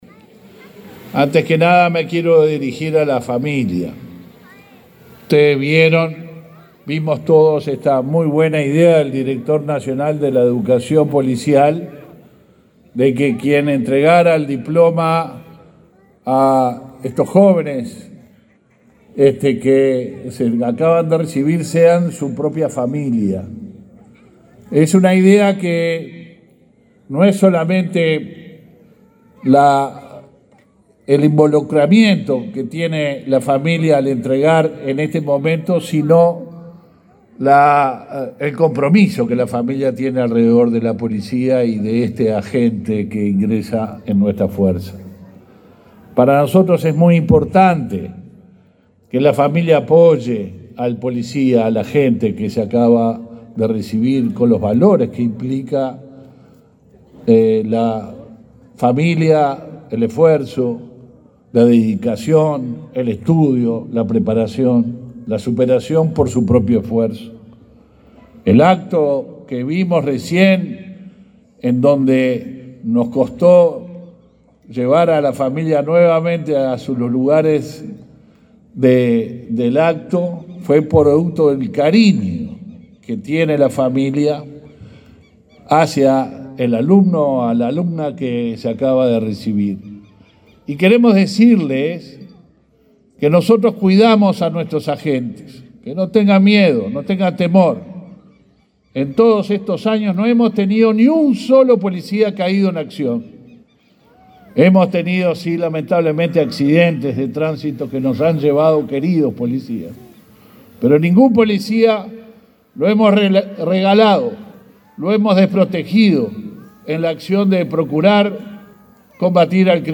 Palabras del ministro del Interior, Luis Alberto Heber
El ministro del Interior, Luis Alberto Heber, participó de la ceremonia de egreso de una nueva promoción de agentes de la Policía Nacional.